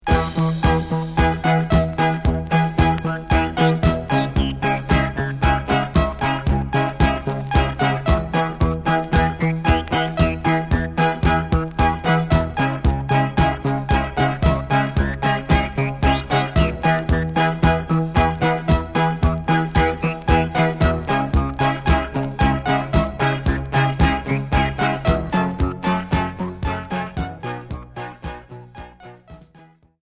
Minimal synth Premier 45t